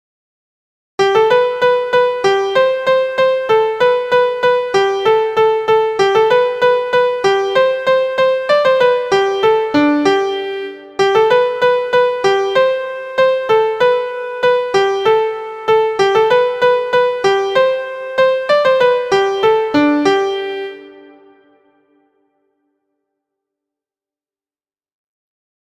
• Origin: United Kingdom, Scotland – Folk Song
• Key: G Major
• Time: 2/4
• Form: Aa – verse/chorus
• Musical Elements: notes: quarter, eighth, sixteenth; rest: eighth; pickup beat, vocal slur